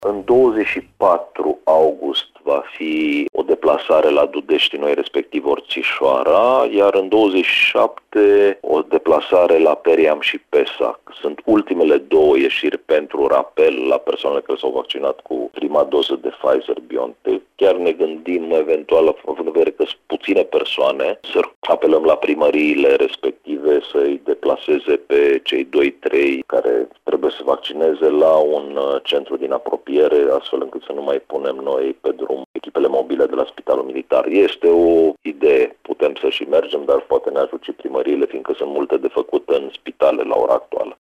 Autoritățile poartă discuții, în acest sens, spune subprefectul de Timiș, Ovidiu Drăgănescu.